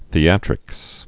(thē-ătrĭks)